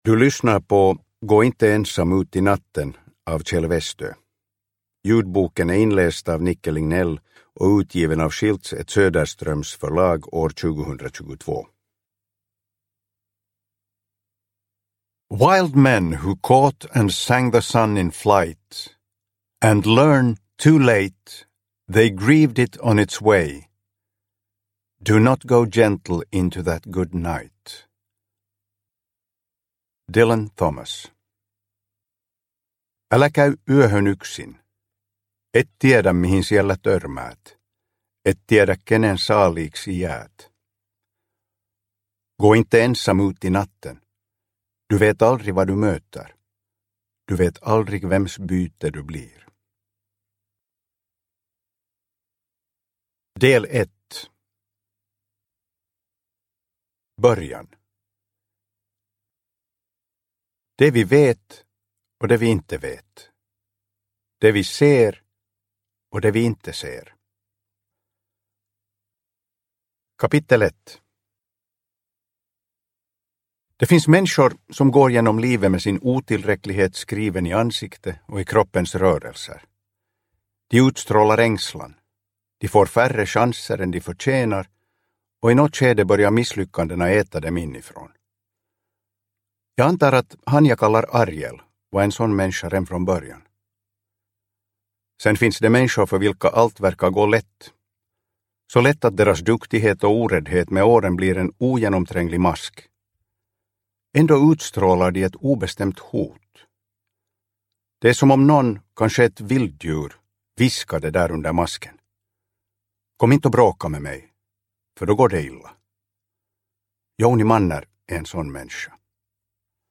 Gå inte ensam ut i natten – Ljudbok – Laddas ner